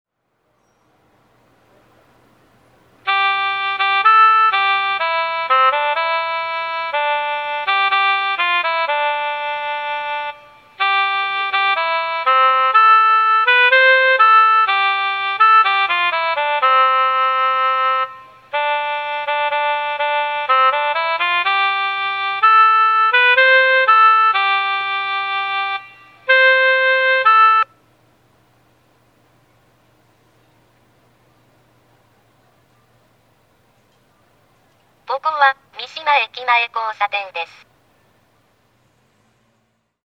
三島駅前(静岡県三島市)の音響信号を紹介しています。